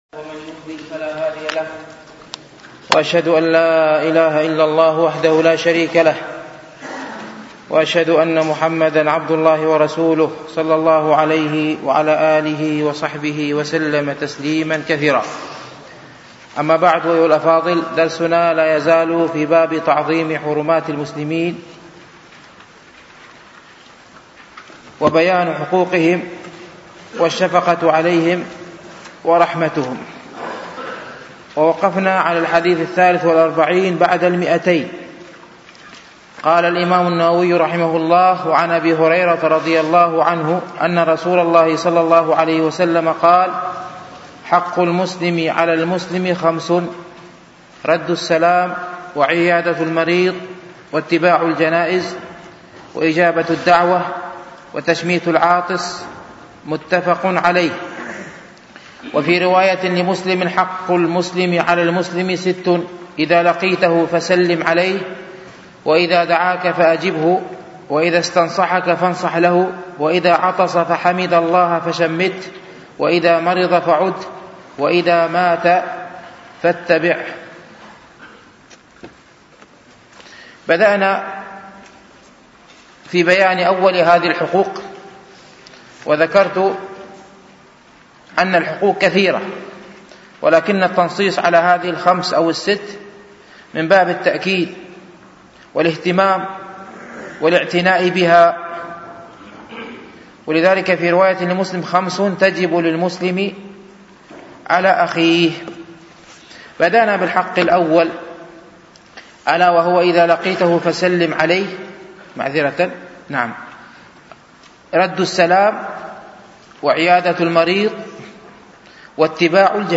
شرح رياض الصالحين ـ الدرس الحادي والستون